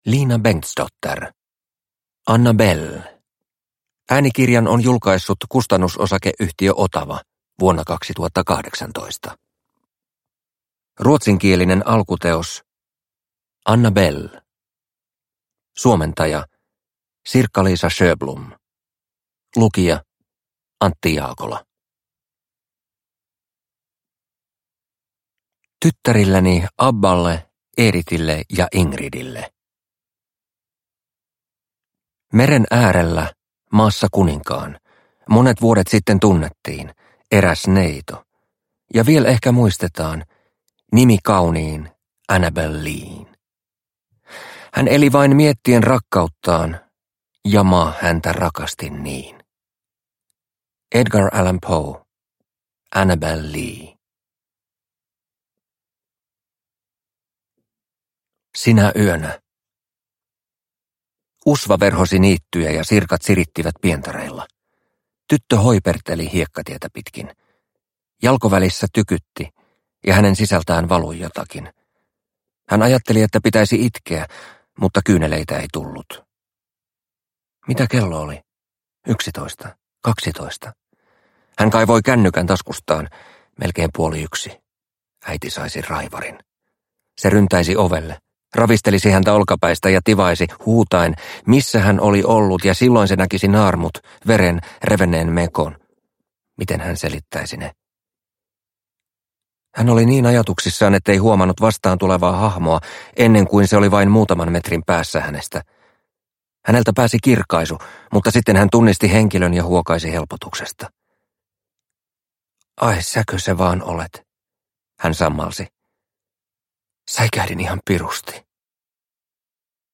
Annabelle – Ljudbok – Laddas ner